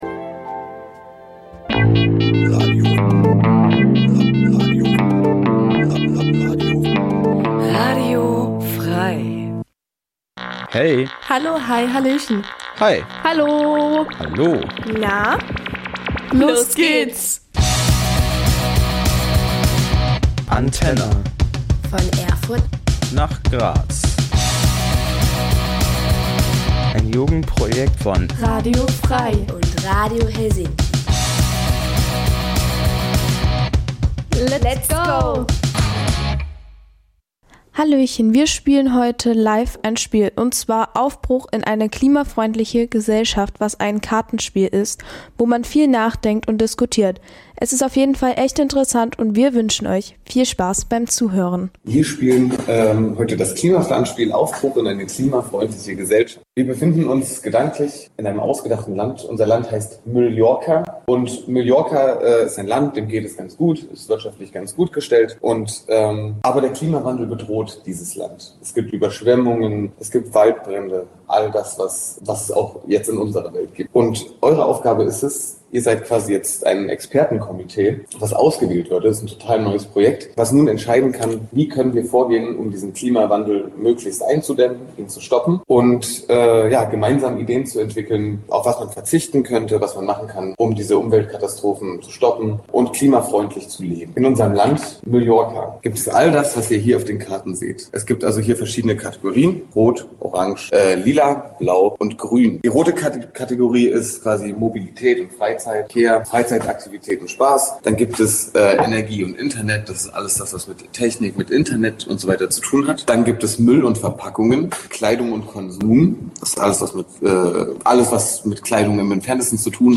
Programm von Kindern und Jugendlichen für Kinder und Jugendliche Dein Browser kann kein HTML5-Audio.